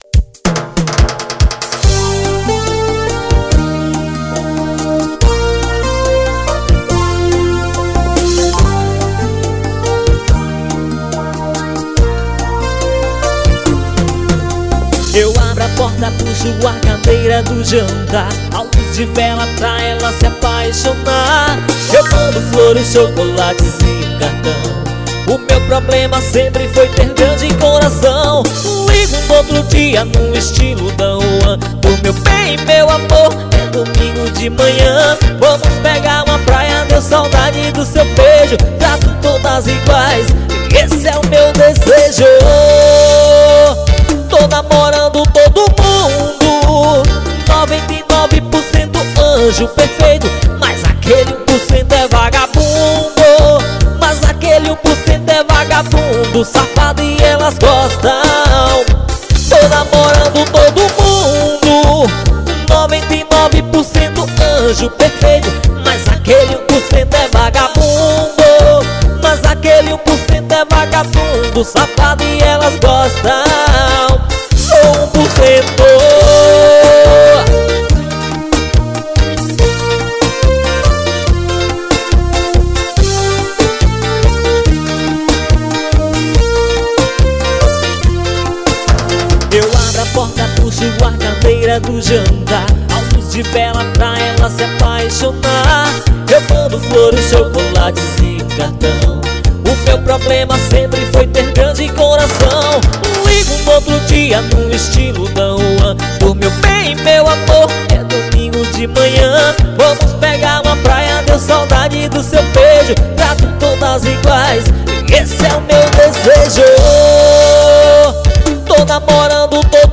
SHOW AO VIVO.